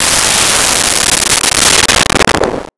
Missle 05
Missle 05.wav